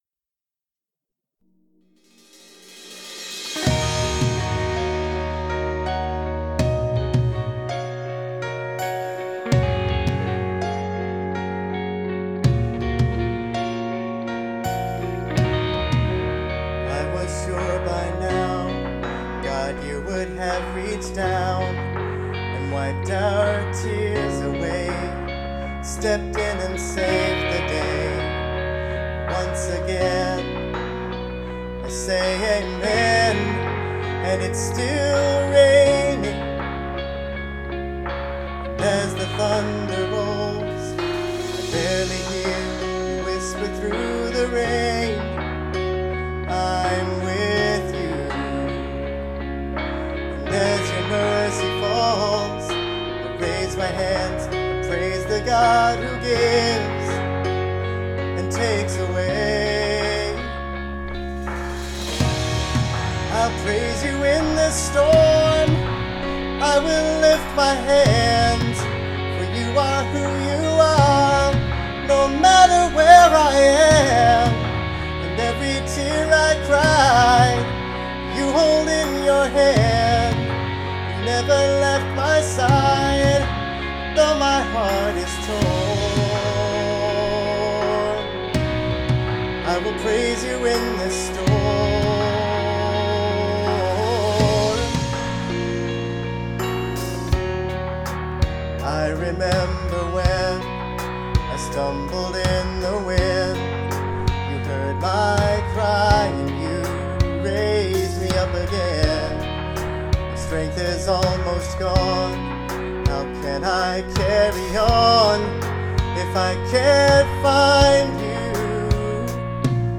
I need a new mic. Both of mine sound bad.